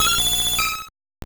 Cri de Persian dans Pokémon Or et Argent.